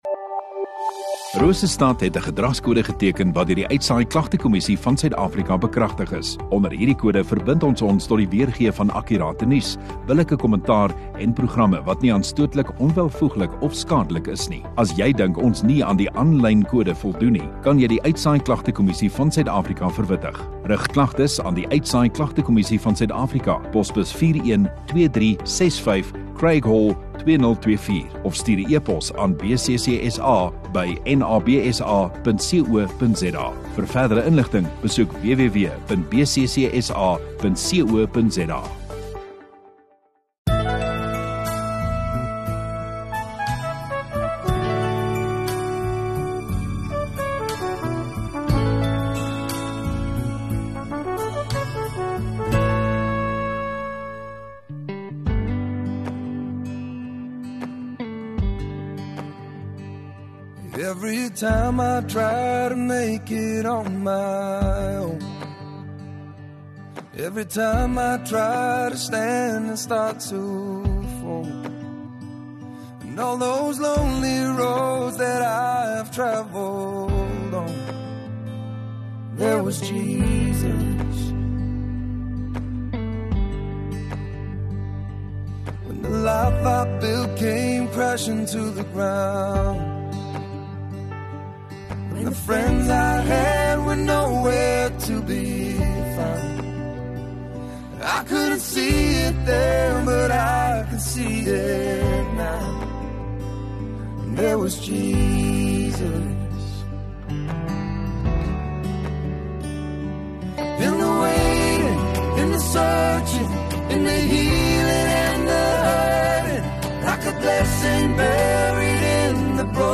30 Nov Sondagaand Erediens